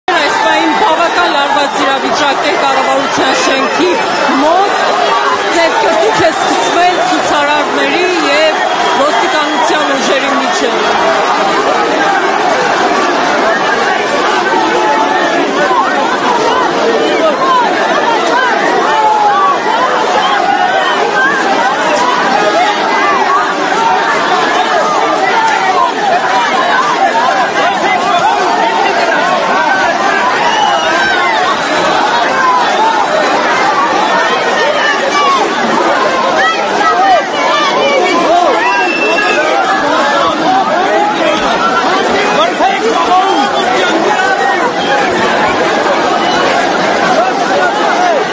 Clashes between protesters and police began in Yerevan, Armenia - the crowd is trying to Break through the cordon in Front of the Parliament building